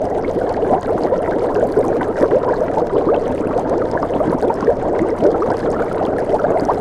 cauldron.ogg